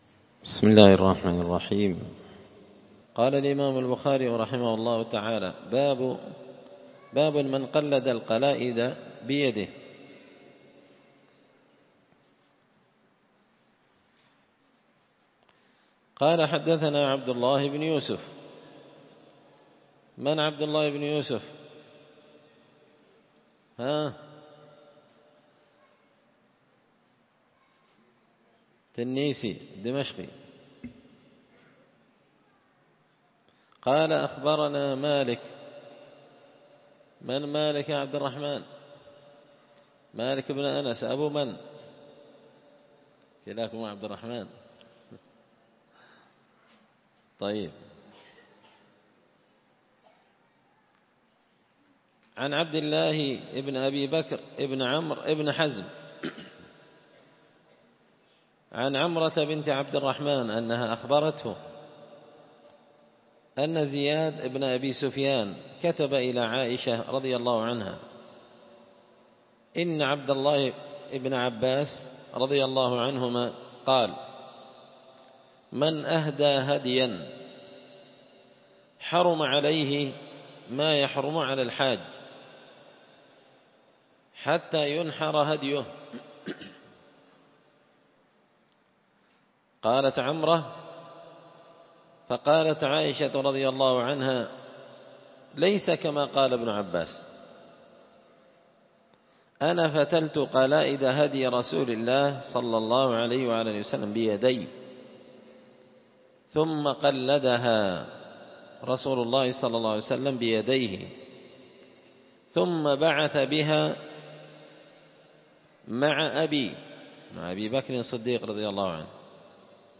كتاب الحج من شرح صحيح البخاري – الدرس 98